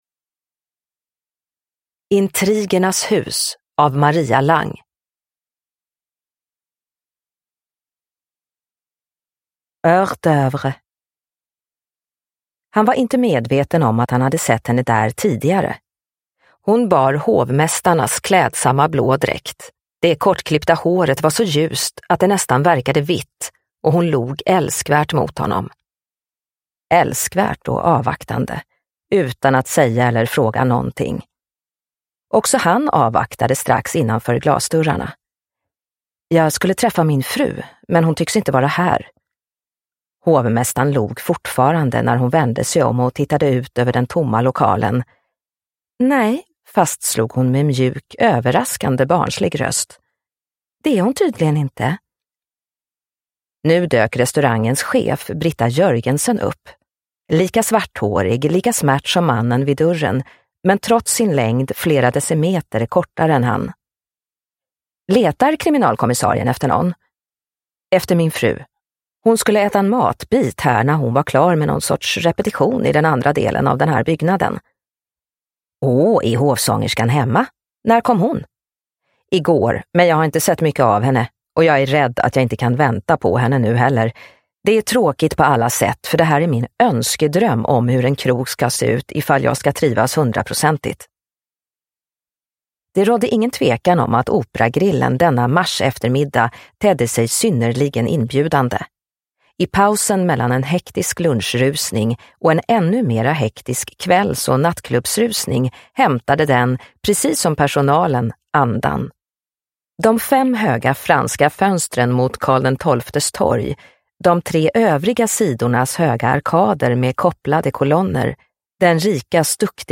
Intrigernas hus – Ljudbok – Laddas ner